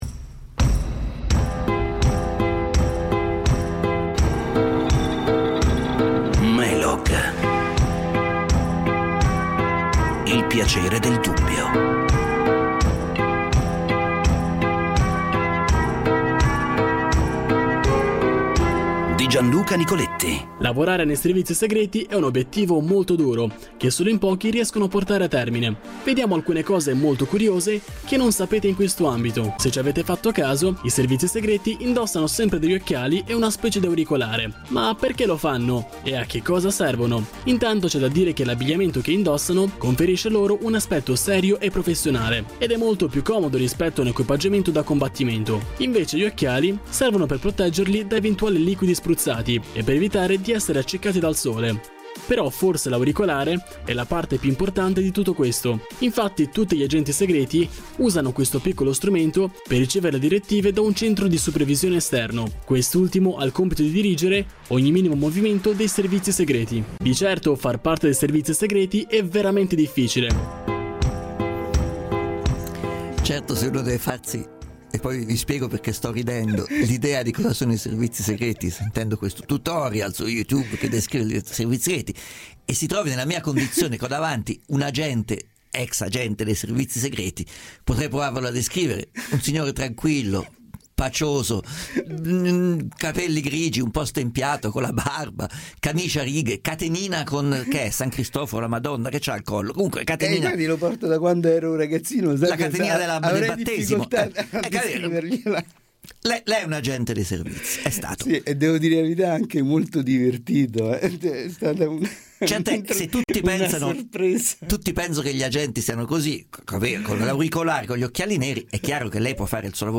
La puntata del programma MELOG andata in onda su Radio24 il 9 maggio 2018, dedicata all'Intelligence italiana.